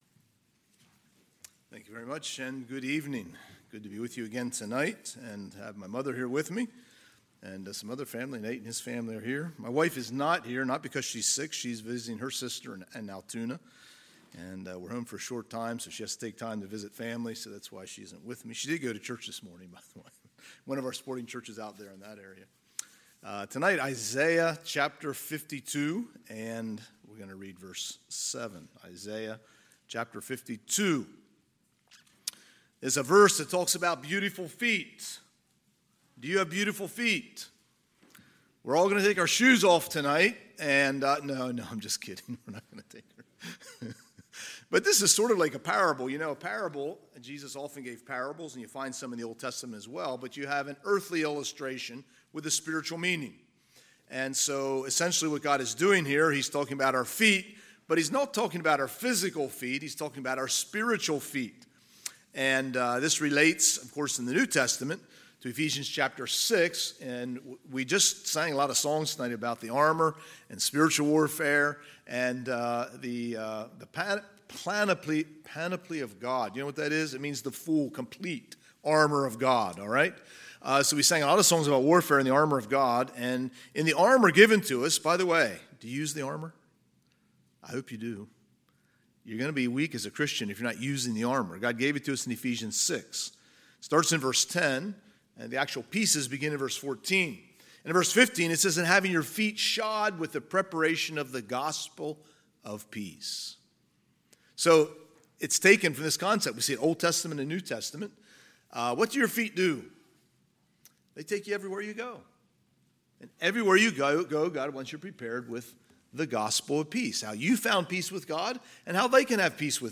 Sunday, September 3, 2023 – Sunday PM